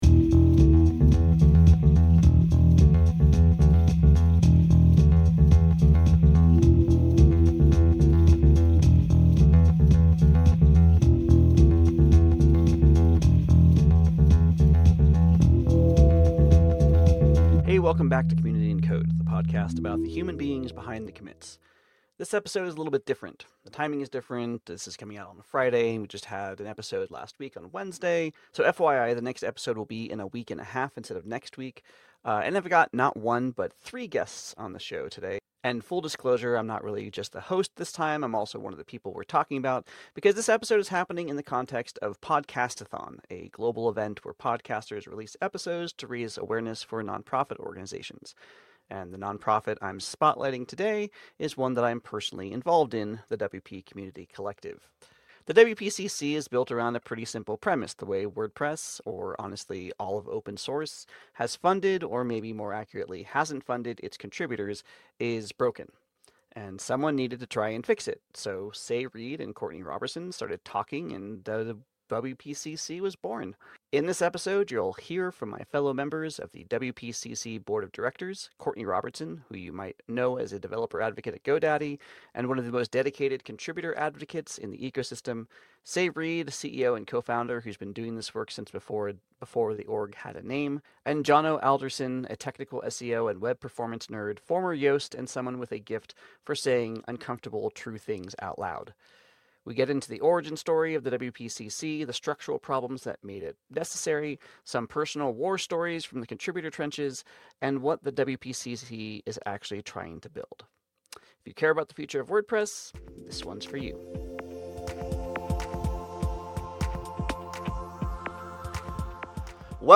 Four members of the WPCC board discuss open source sustainability, contributor burnout, and what the WP Community Collective is building.